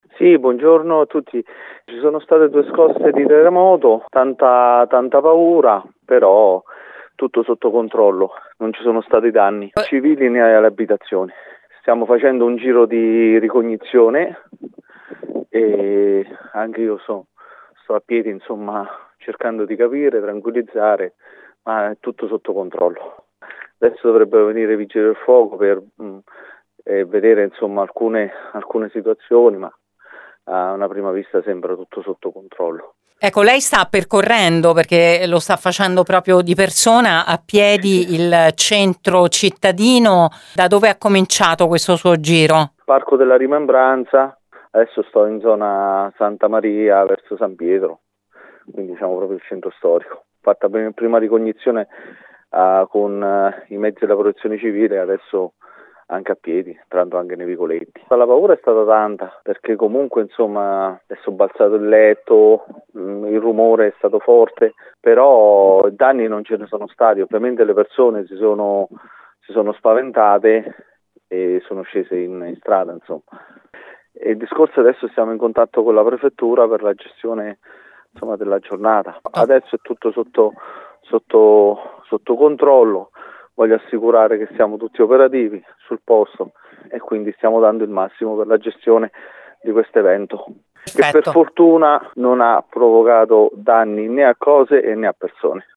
Abbiamo raggiunto al telefono il primo cittadino mentre effettuava a piedi un giro di ricognizione con due squadre della protezione civile
sindaco-lucidi-terremoto.mp3